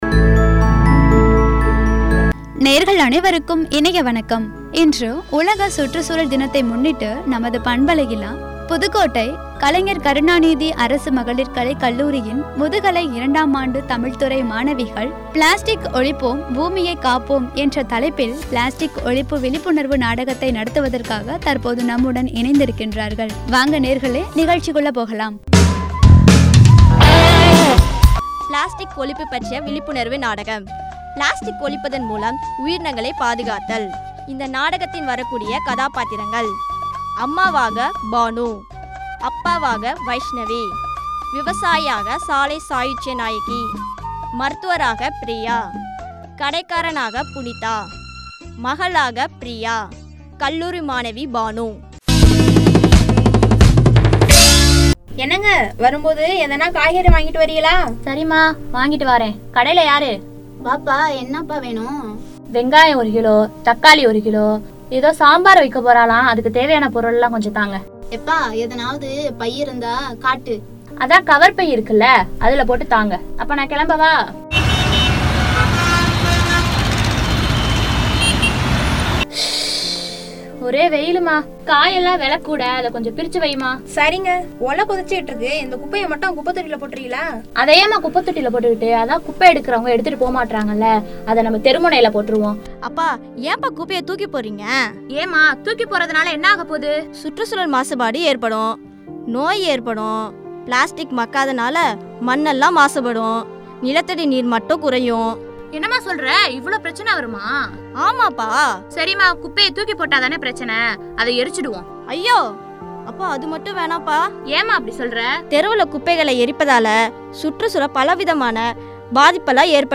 “ (விழிப்புணர்வு நாடகம்)